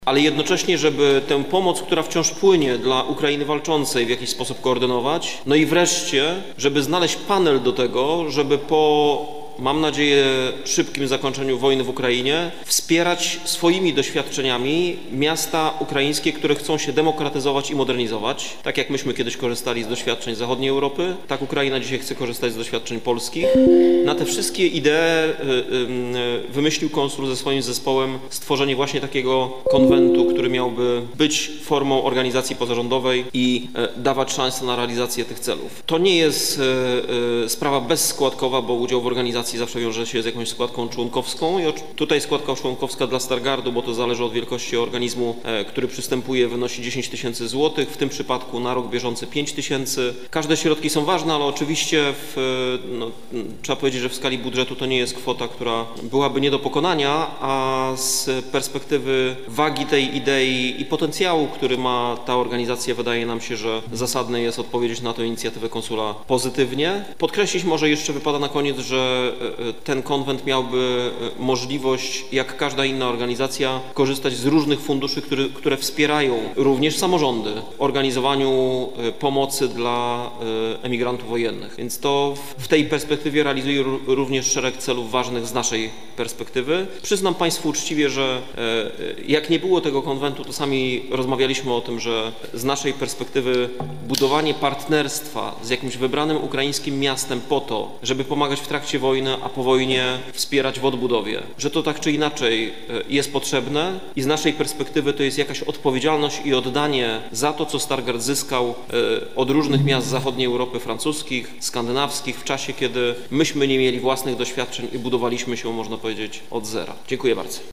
Podczas LIII sesji Rady Miejskiej w Stargardzie, która odbyła się we wtorek 20 czerwca 2023 r. radni jednogłośnie, wypowiedzieli się za przystąpieniem Gminy-Miasta Stargard do Konwentu Współpracy Samorządowej Polska-Ukraina.